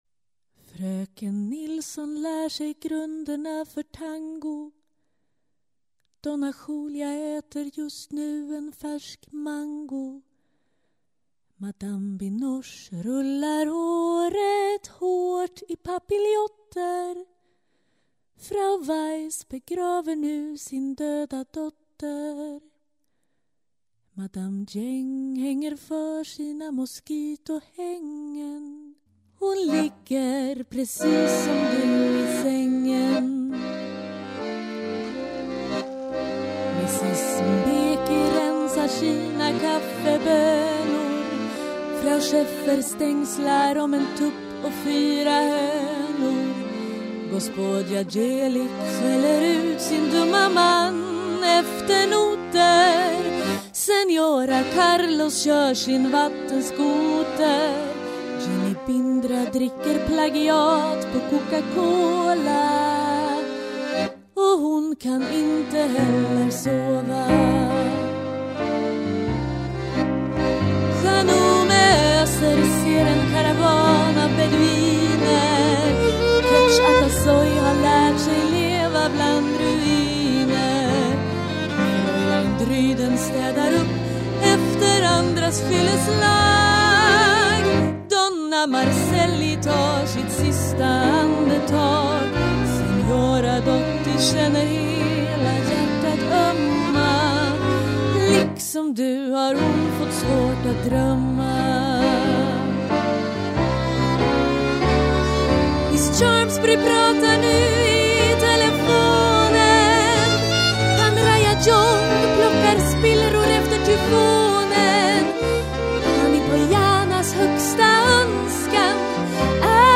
piano, dragspel, sång
Saxofon
Fiol
Trumprogrammering & synth
Cello